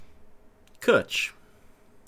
Cwtch /kʊtʃ/